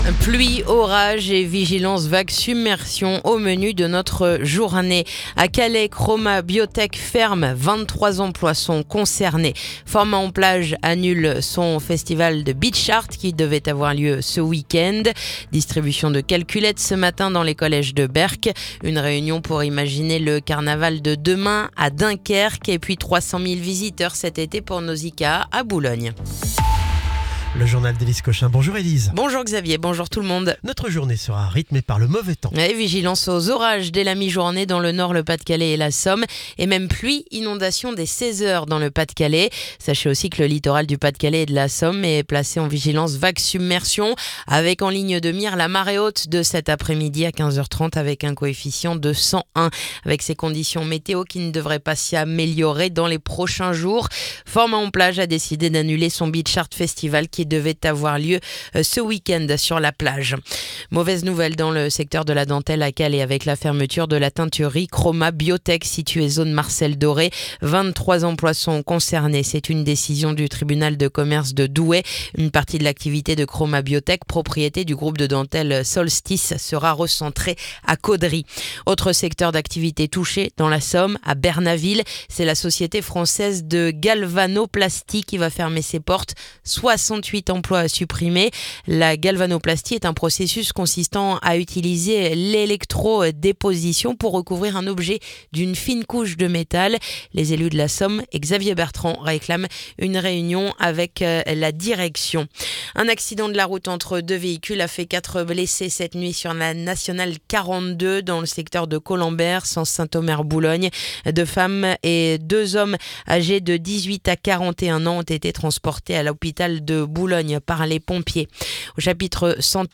Le journal du jeudi 11 septembre